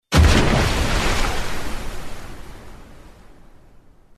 missile_water.mp3